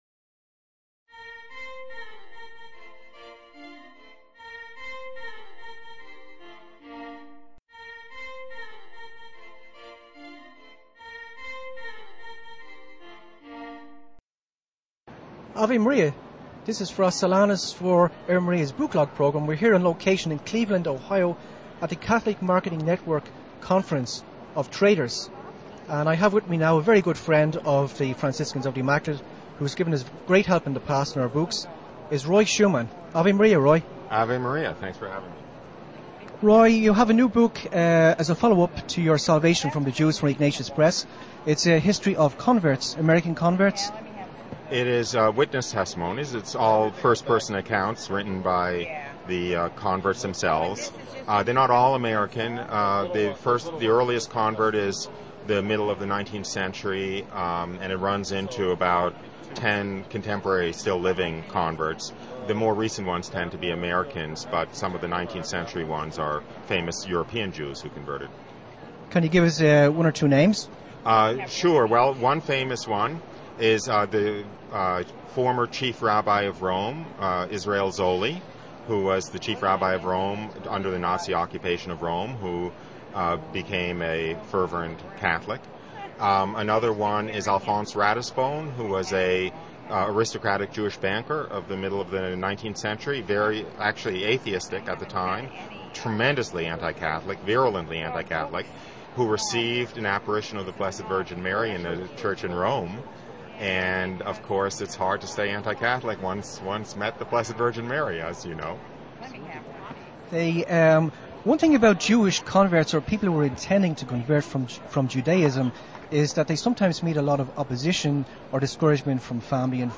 Book Log #3 - Interview
at the Catholic Marketing Network trade show in Cleveland